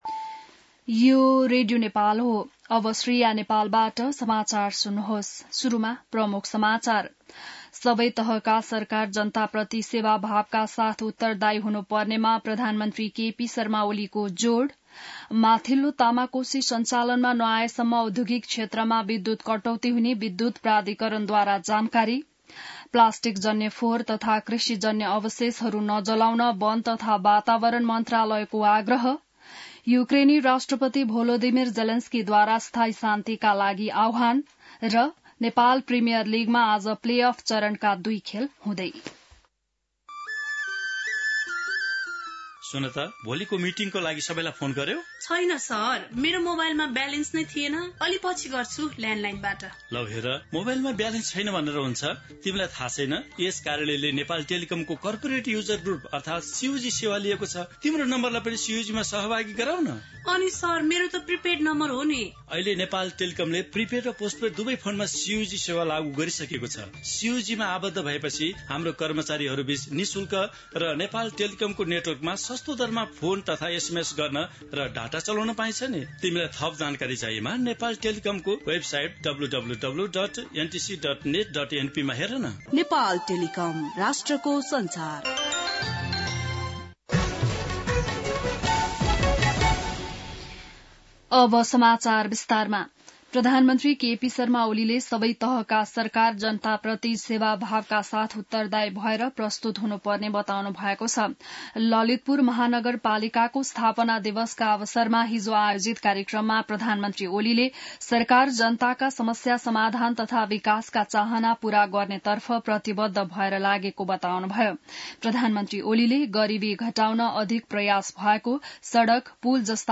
बिहान ७ बजेको नेपाली समाचार : ४ पुष , २०८१